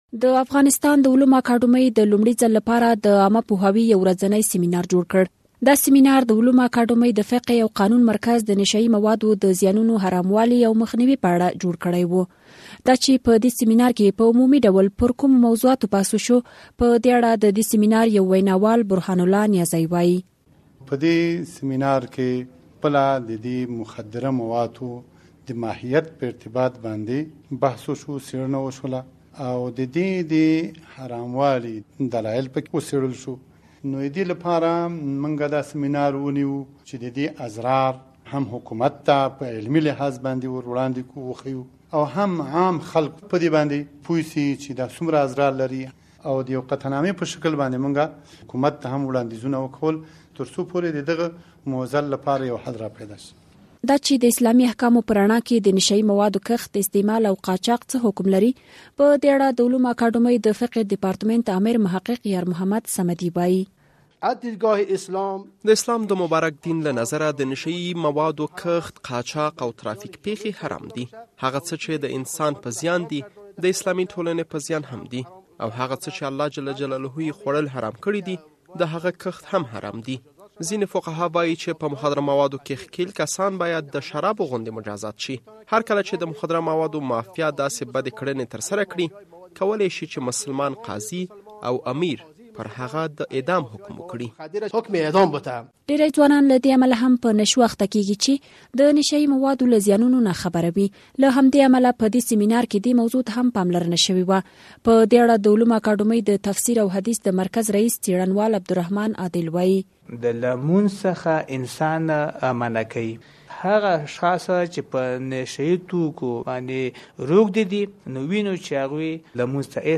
اوس به دا واورو چې دا ډول سمينارونه د عامو خلکو له نظره څومره مؤثر او ګټور دي.